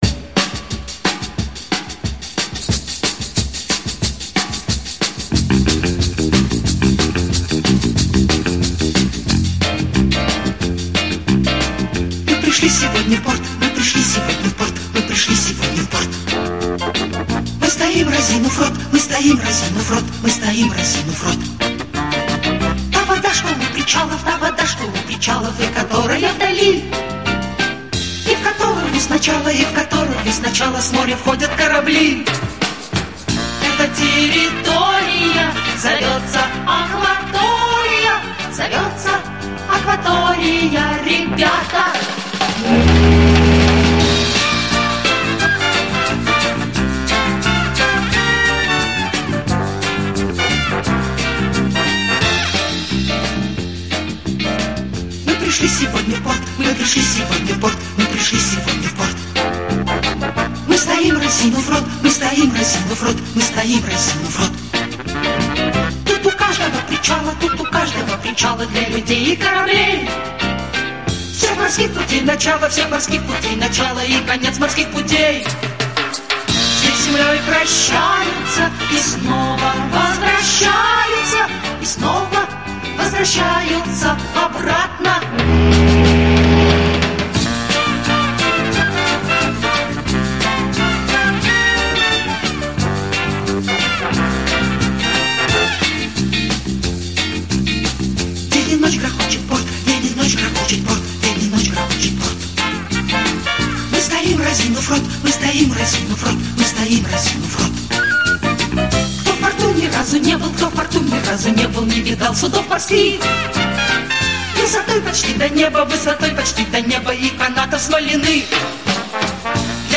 Захватывающая детская песня